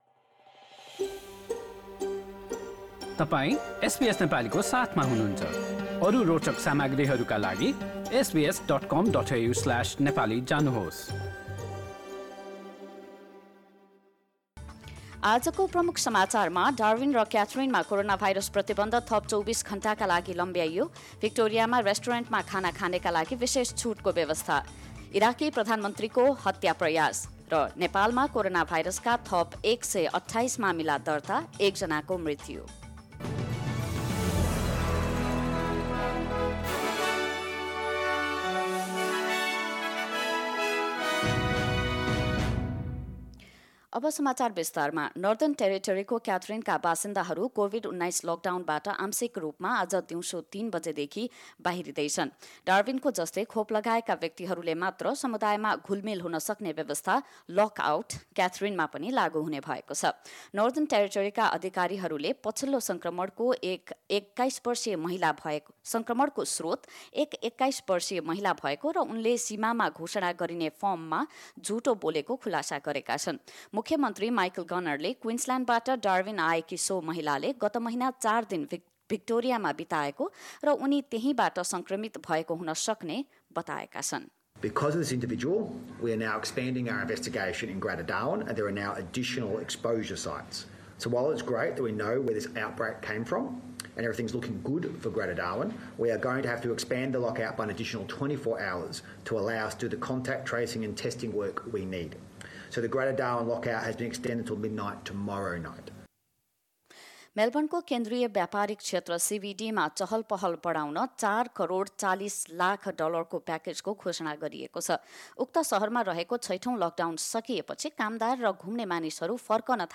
nepali_71121_newsheadlines.mp3